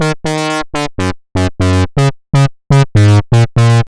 Ultra Riff_122_Eb.wav